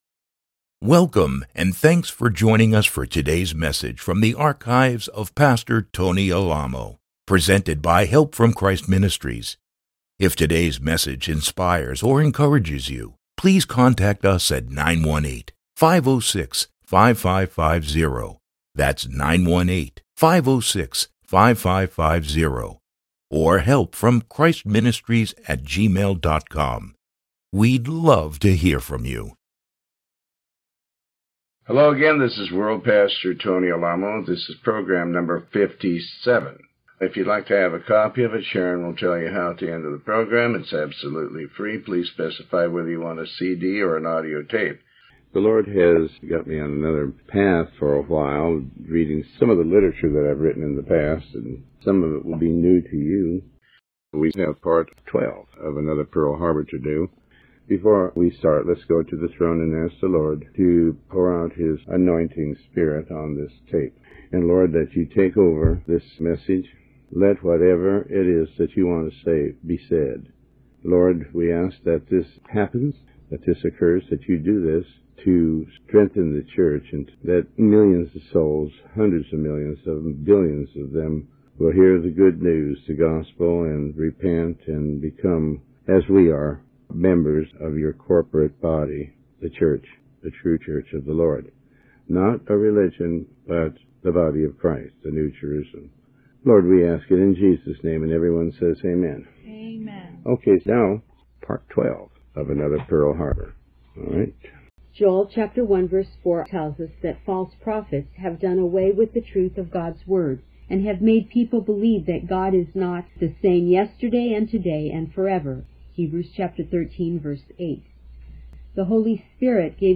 Sermon 57B